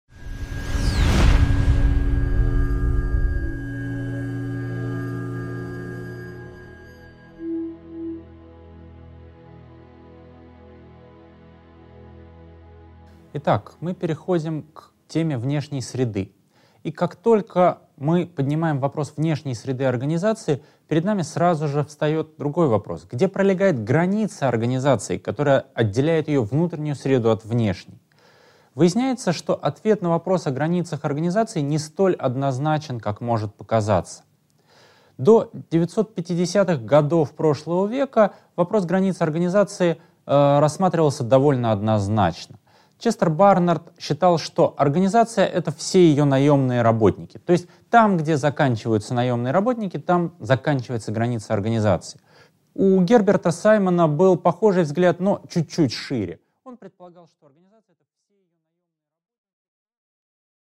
Аудиокнига 6.2. Проблема границ организации | Библиотека аудиокниг